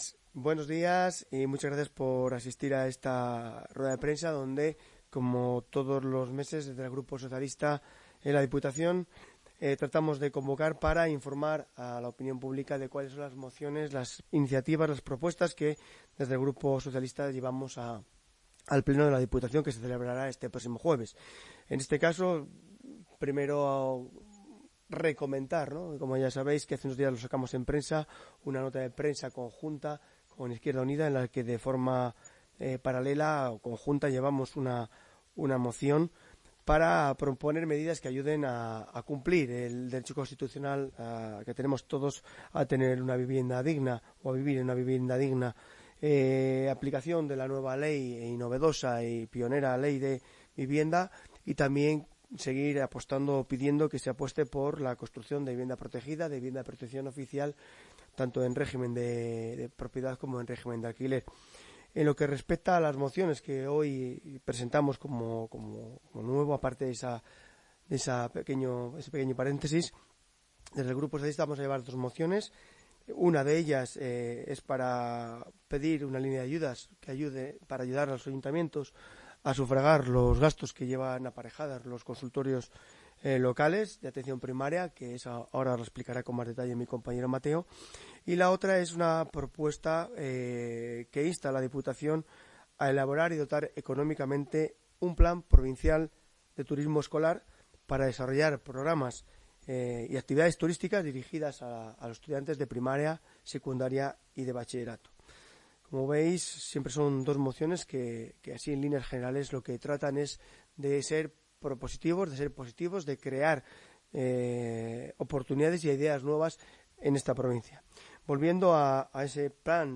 “Con ambas propuestas los socialistas trabajamos para mejorar la vida de las personas, que es una responsabilidad compartida que nos involucra a todos como individuos, representantes de la ciudadanía e instituciones. En el PSOE estamos inmersos en la búsqueda constante de soluciones innovadoras y acciones concretas que generen un impacto positivo en la sociedad, para que Segovia sea una tierra de prosperidad, progreso y esperanza”, ha declarado en rueda de prensa Máximo San Macario, portavoz del Grupo Socialista, acompañado por José Antonio Mateo, viceportavoz socialista.